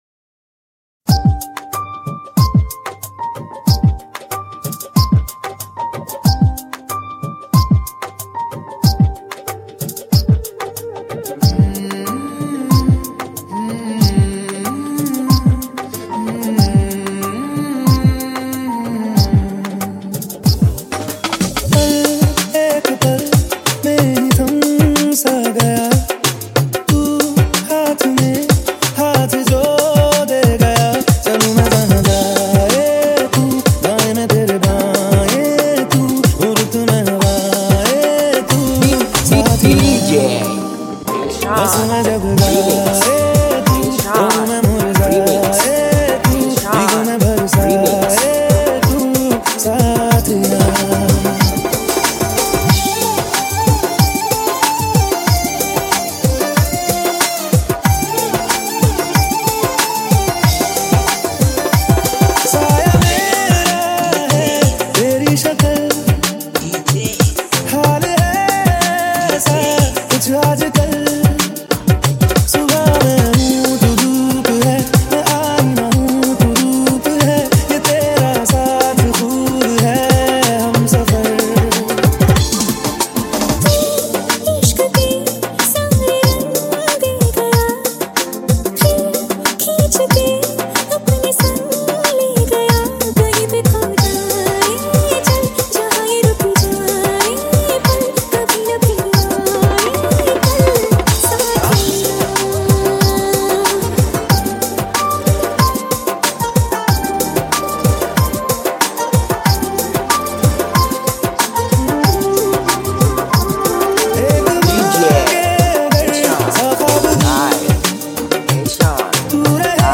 High quality Sri Lankan remix MP3 (4.1).